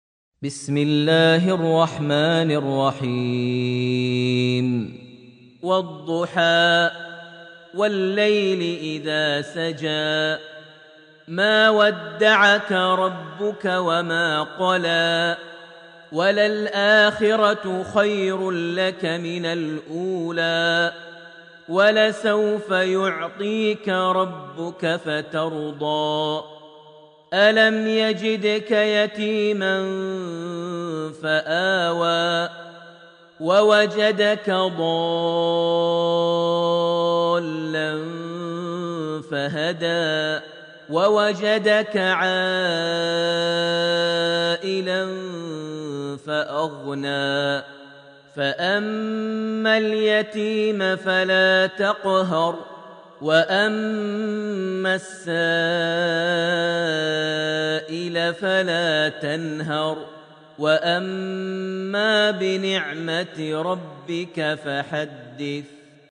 surat Al-Duha > Almushaf > Mushaf - Maher Almuaiqly Recitations